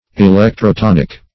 Electrotonic \E*lec`tro*ton"ic\, a.
electrotonic.mp3